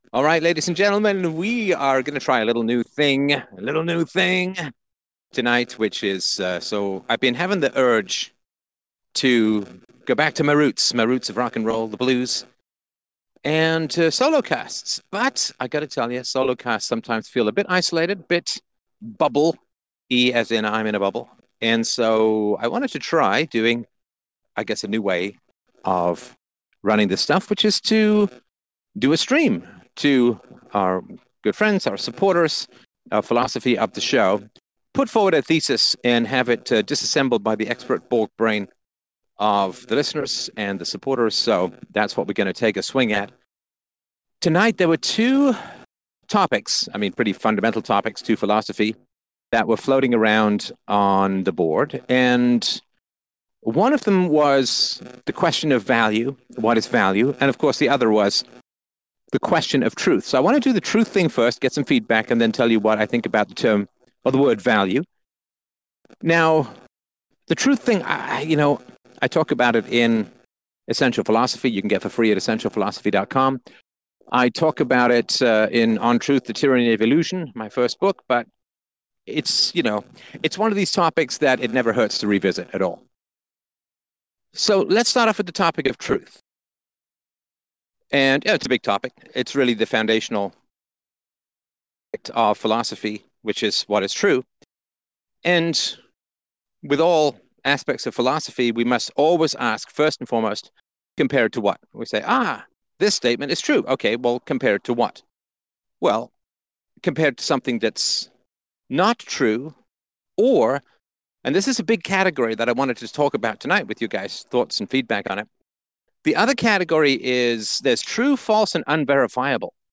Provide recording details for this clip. A new podcast format with live listener feedback! What is the relationship between "truth" and "value"?